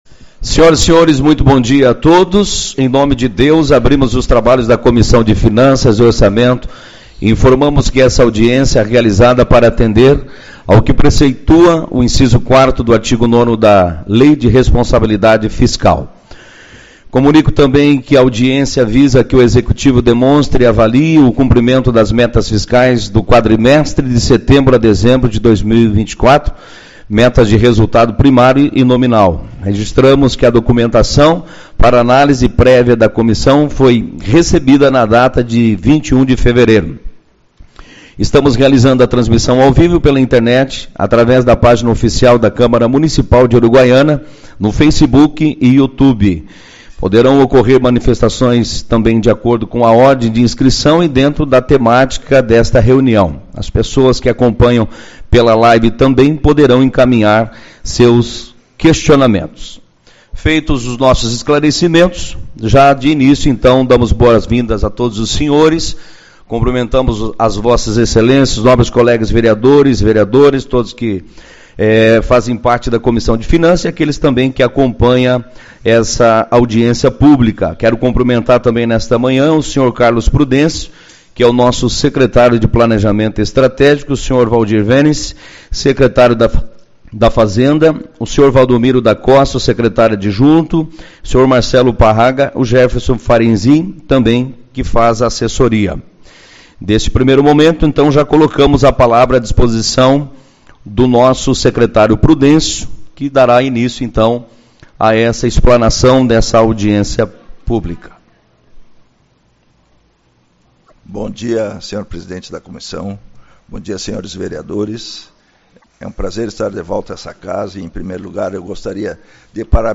Tipo de Sessão: Audiência Pública